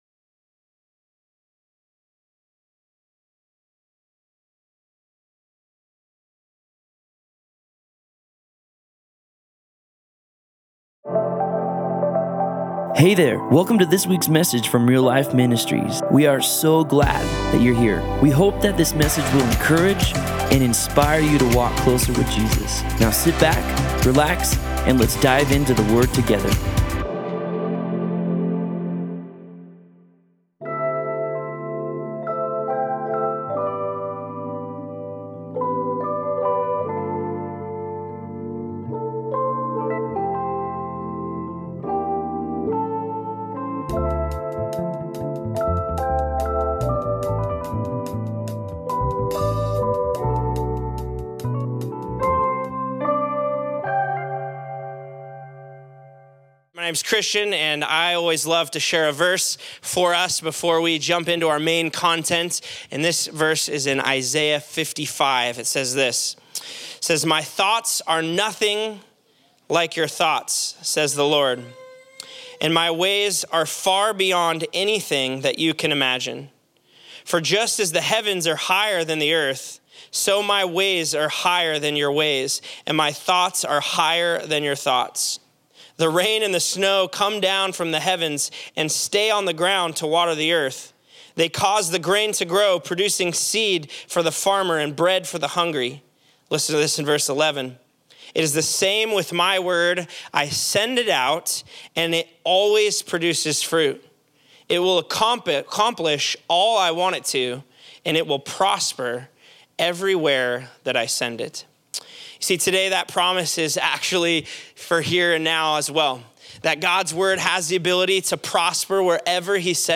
I+Am+Wk8+Sermon+Audio+CDA.mp3